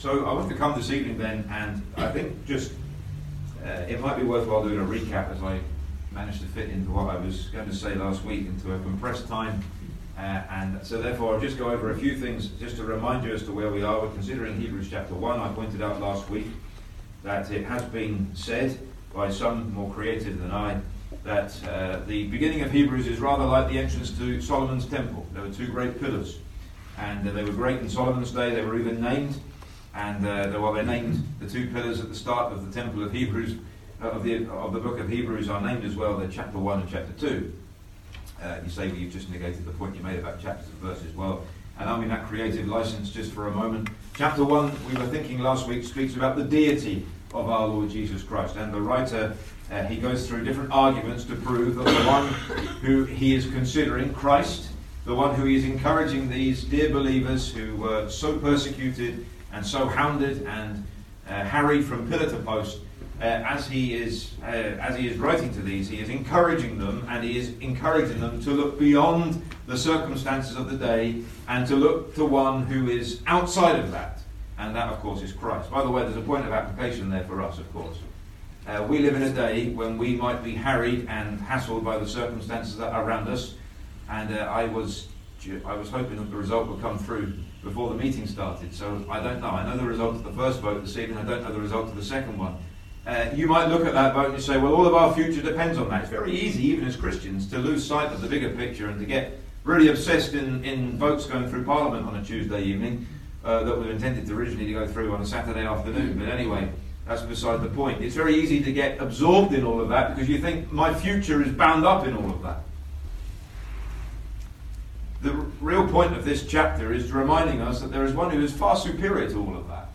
Service Type: Ministry